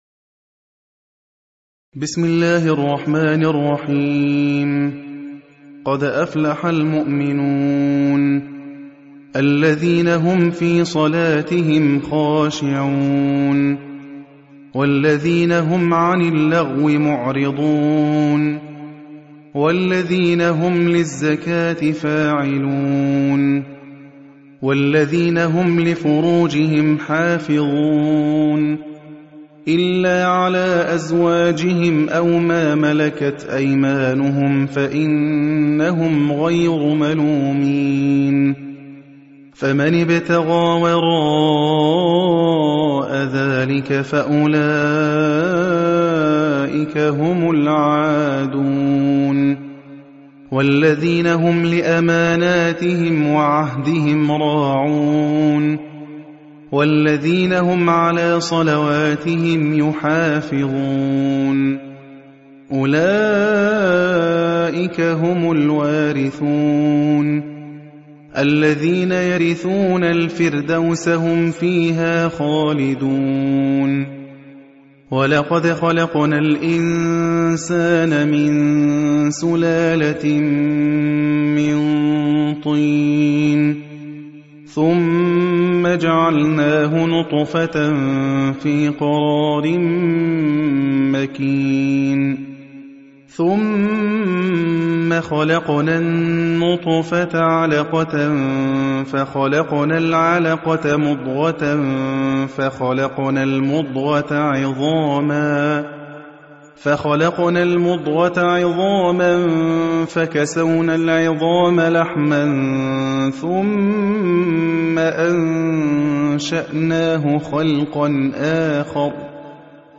Riwayat Hafs from Asim